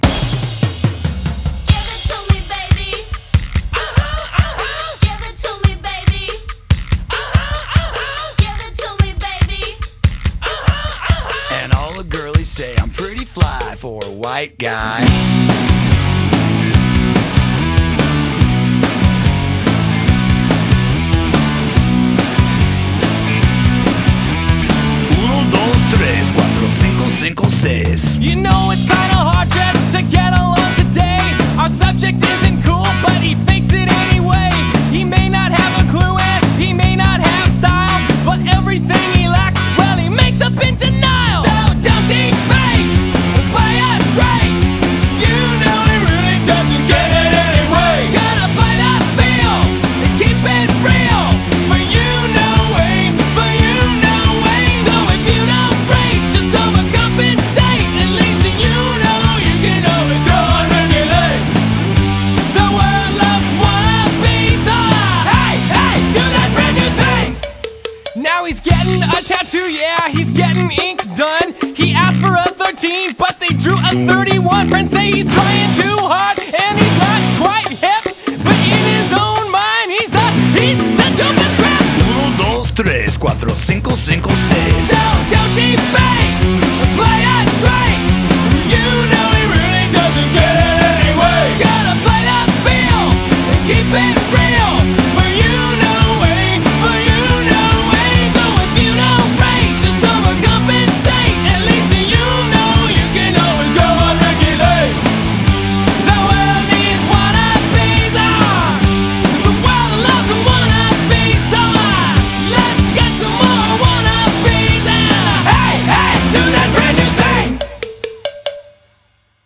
BPM142-145
Audio QualityLine Out